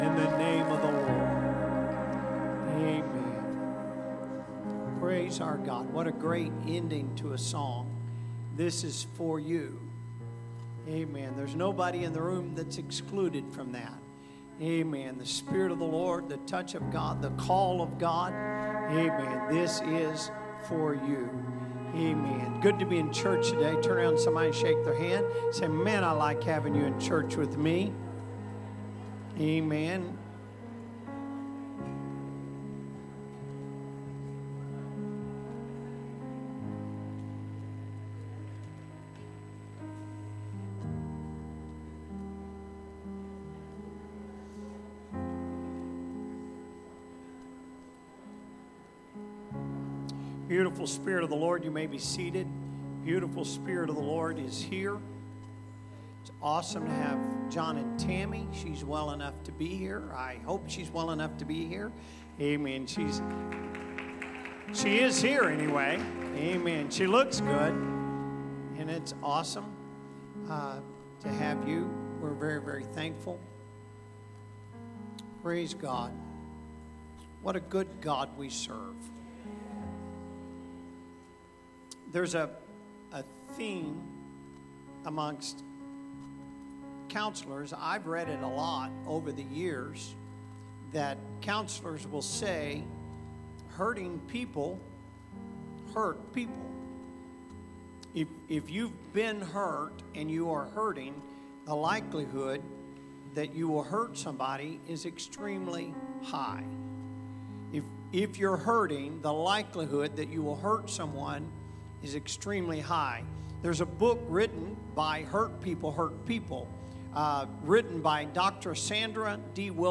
Sunday Service - Part 1